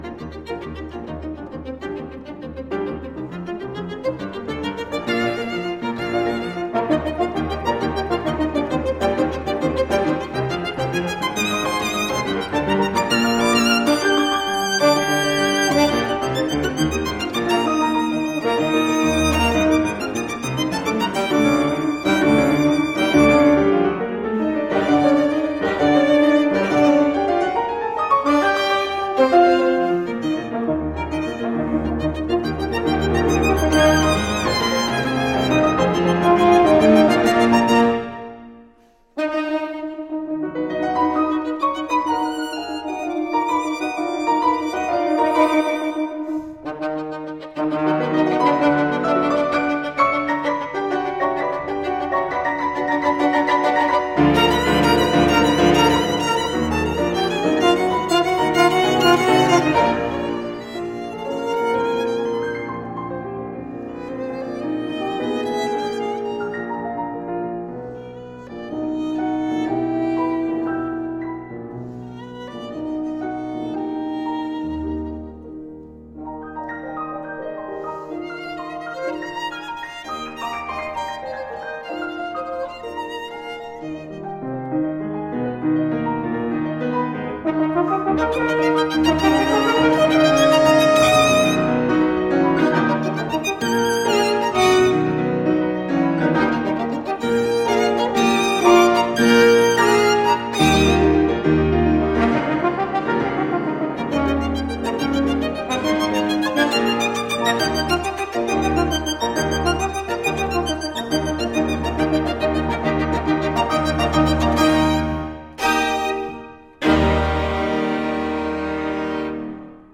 Romantic Repertoire for violin, horn and piano